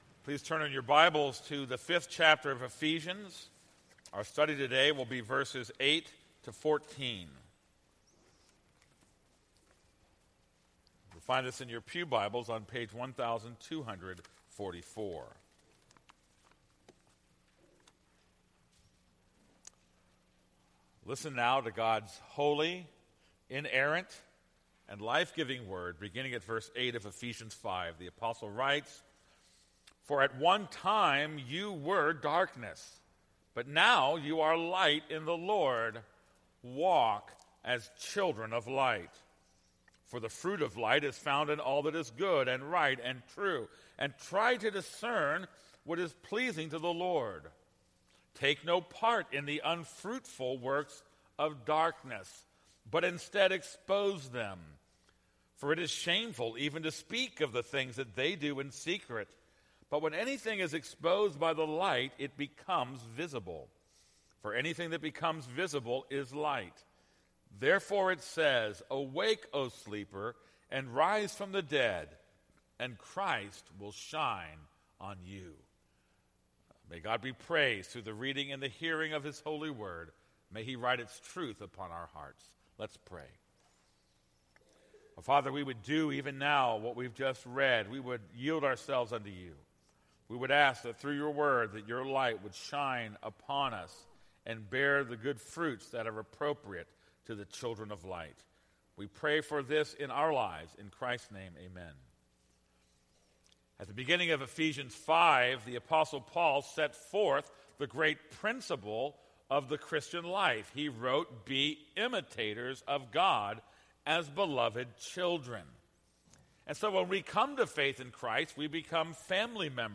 This is a sermon on Ephesians 5:8-14.